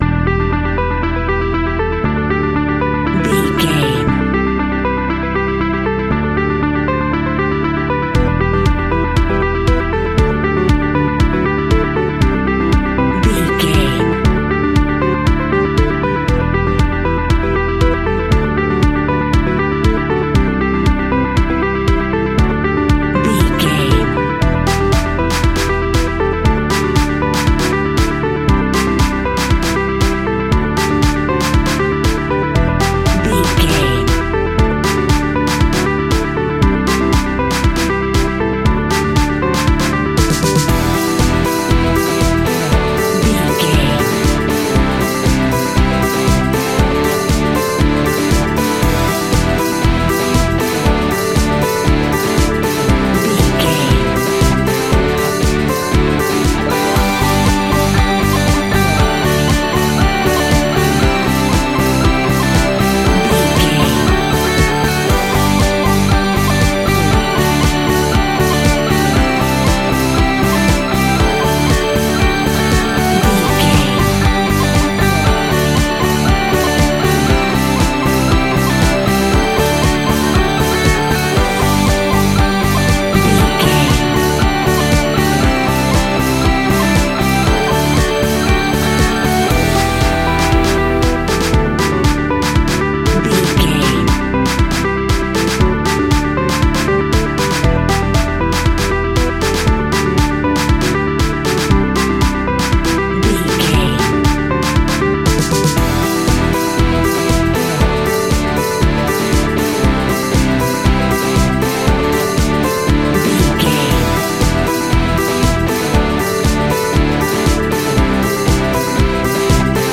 Electro Indie Pop Rocked Up.
Ionian/Major
pop rock
fun
energetic
uplifting
instrumentals
upbeat
rocking
groovy
guitars
bass
drums
piano
organ